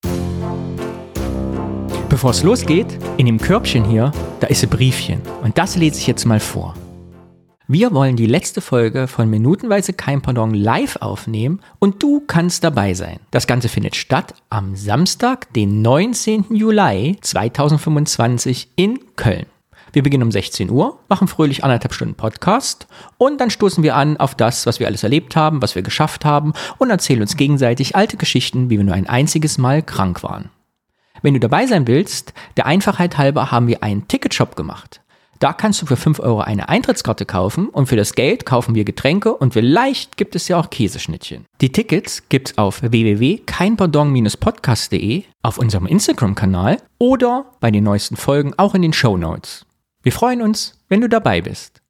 Live beim CologneComedyFestival mit Hape Kerkeling - Minutenweise "Kein Pardon" der Film - Подкаст